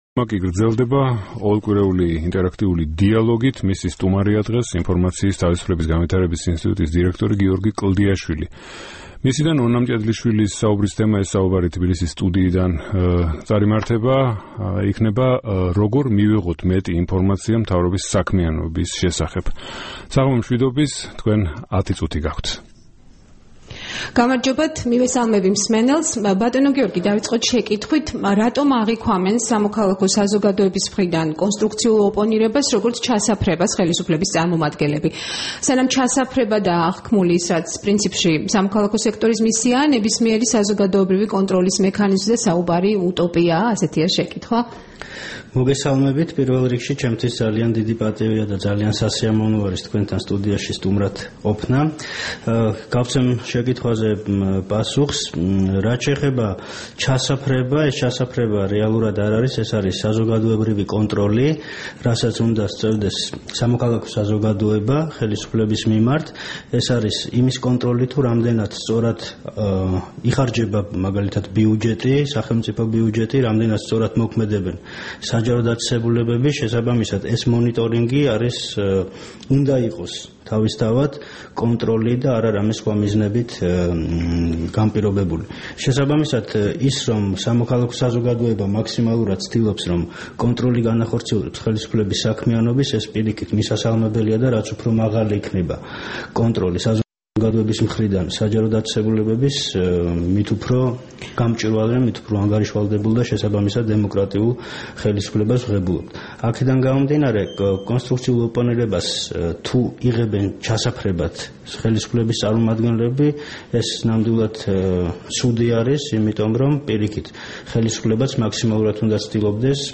„ინტერაქტიული დიალოგის“ სტუმარი